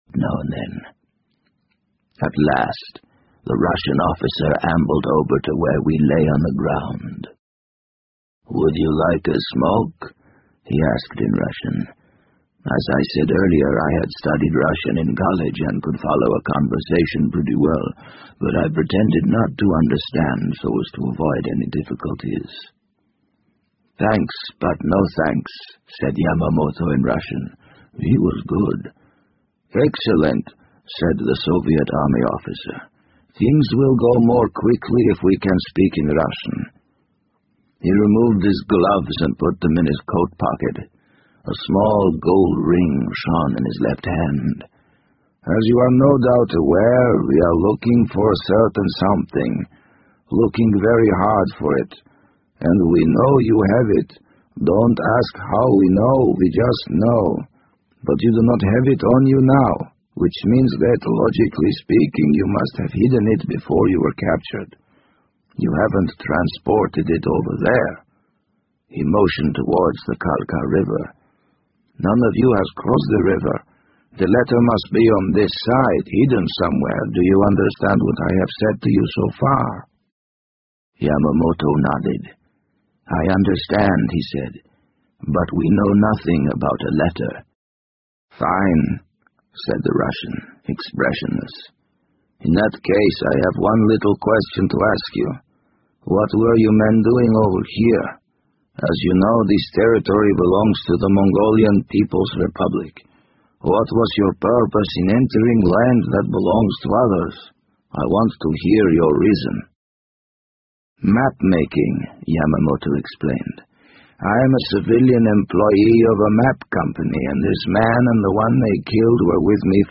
BBC英文广播剧在线听 The Wind Up Bird 004 - 16 听力文件下载—在线英语听力室